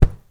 sfx_impact_pillow_04.wav